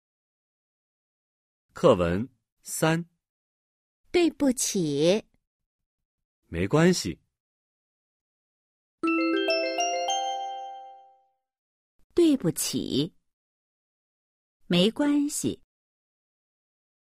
#3. Hội thoại 3